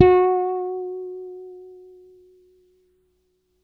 -MM BRYF F#5.wav